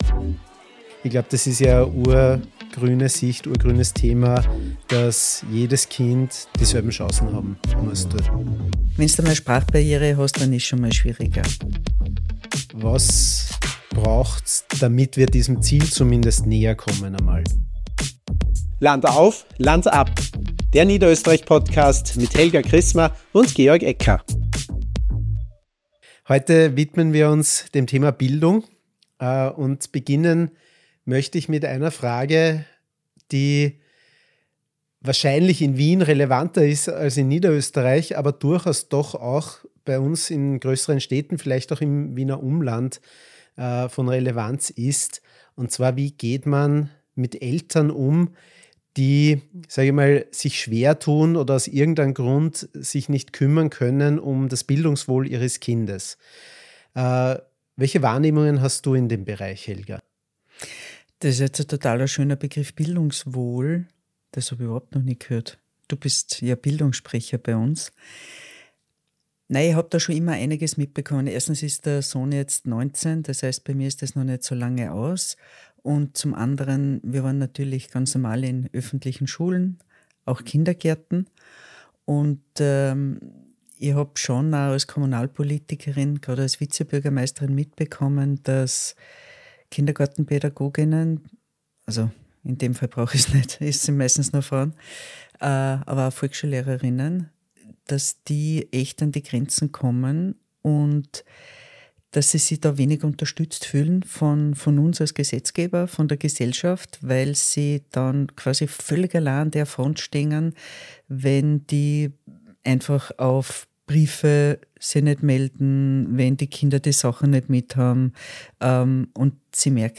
Helga Krisma und Georg Ecker sprechen über die Herausforderungen und Chancen im Bildungssystem Niederösterreichs. Im Mittelpunkt steht die Frage: Wie können wir echte Chancengleichheit für alle Kinder schaffen – unabhängig von Herkunft, Sprache oder sozialem Status?